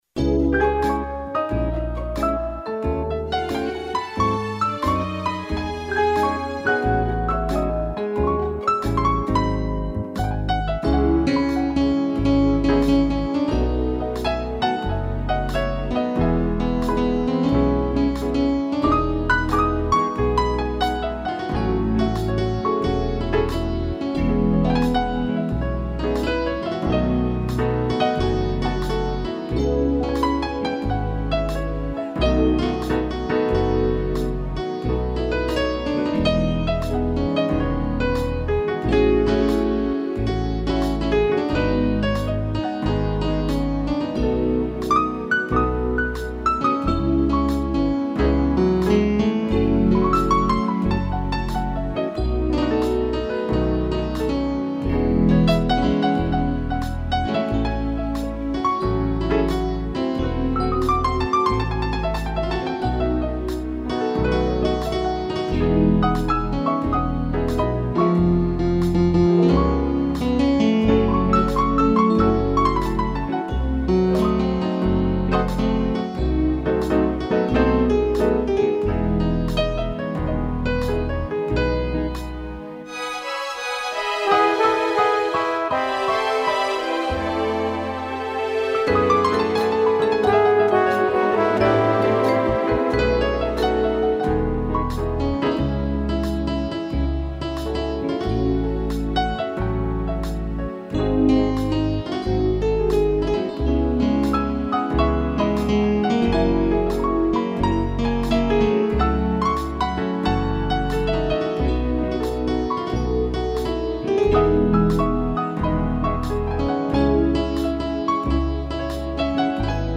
instrumental
piano